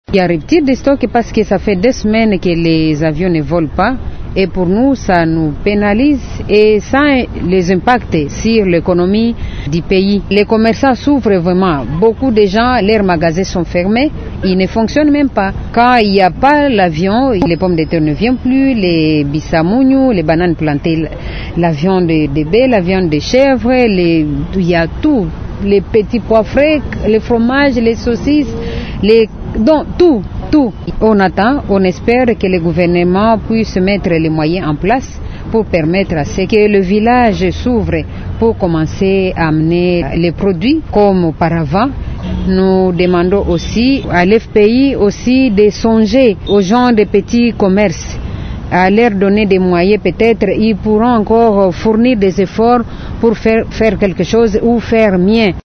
Lors d’un point de presse tenu vendredi ici à Kinshasa, elle a expliqué que l’éruption volcanique a entrainé une rupture de stocks entre les opérateurs économiques et les clients basés dans la ville de Kinshasa, par rapport à certains produits.